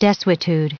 1743_desuetude.ogg